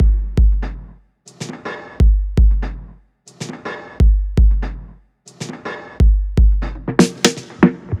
Сэмплы ударных (Electro Dub): Snare Kit C
Sound_12398_SnareKitC.ogg